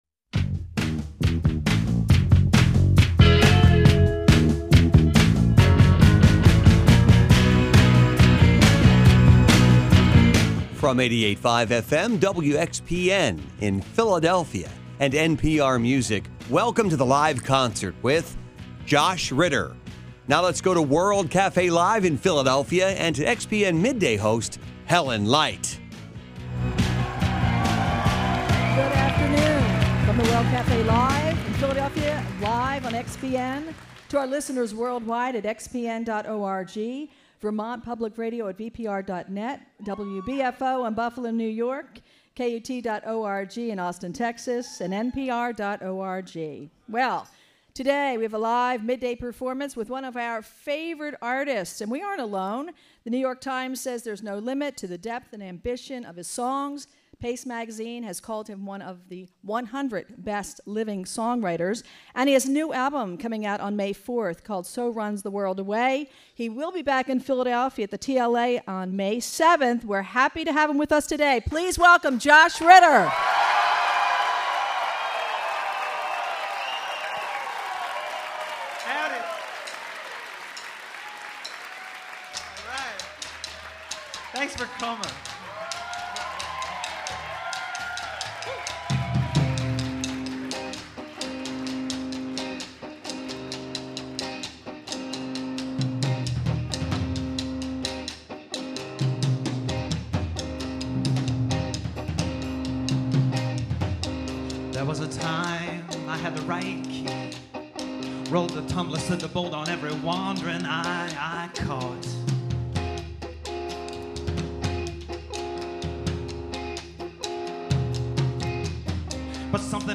Influenced by classic rock songwriters
folk-rock singer